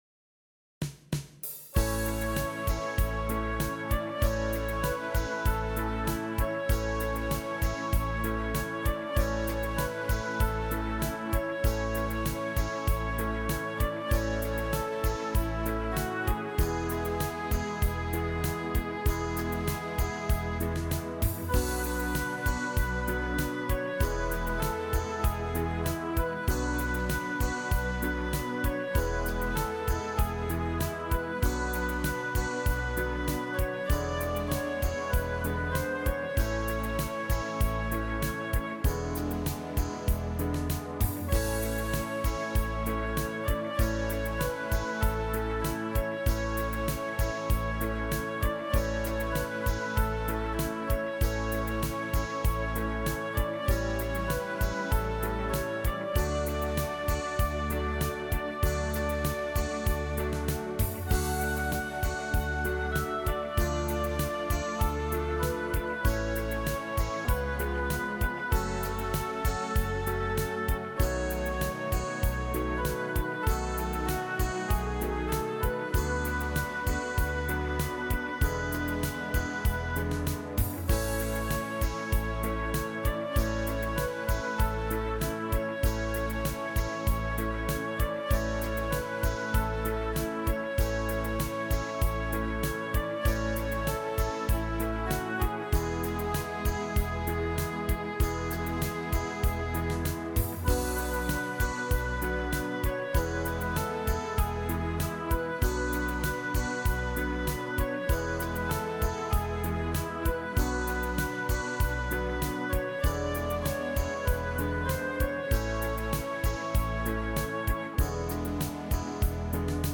Soft Beat